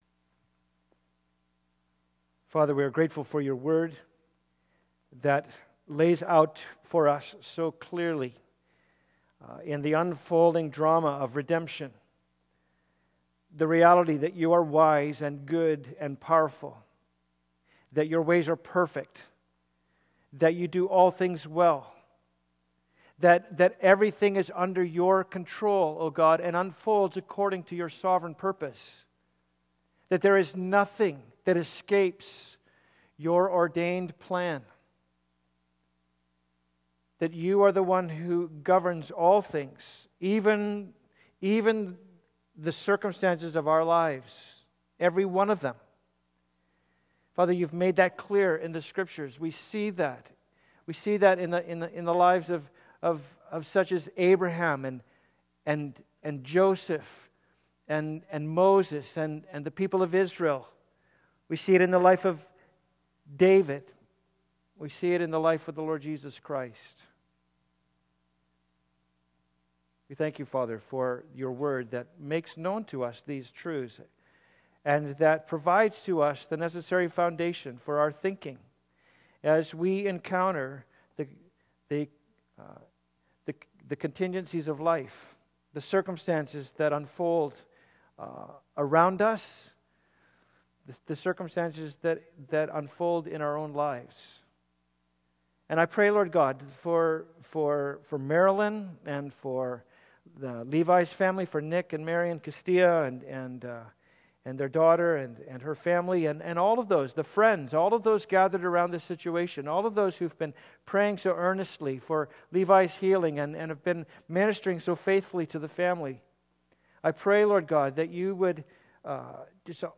Passage: Mark 8:27-38 Service Type: Sunday Service Topics